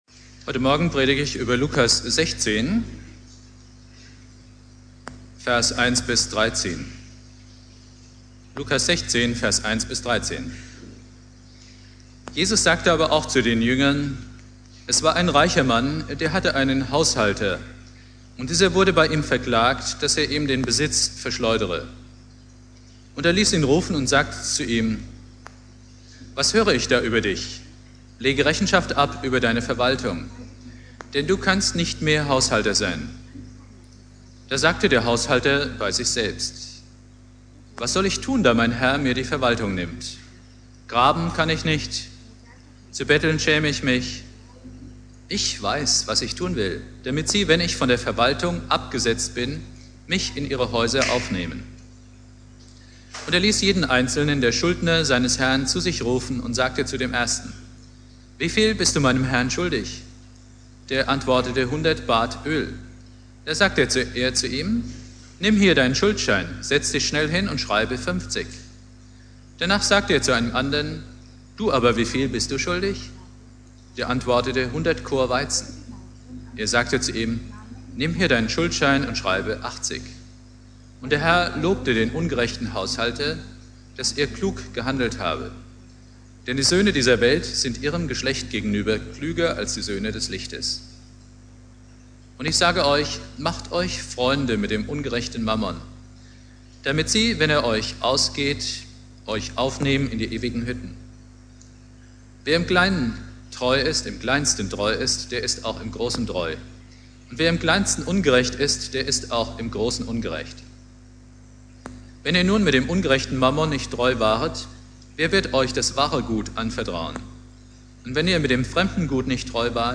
Thema: Das Gleichnis vom unehrlichen Verwalter - "Geld regiert die Welt?" Inhalt der Predigt: Relativ schlechte Aufnahmequalität - die Stimmen im Hintergrund stammen von einem Mittelwellen-Radiosender, der durch ein nicht gut abgeschirmtes Mikrofonkabel hörbar wurde. Bibeltext: Lukas 16,1-13 Dauer: 26:02 Abspielen: Ihr Browser unterstützt das Audio-Element nicht.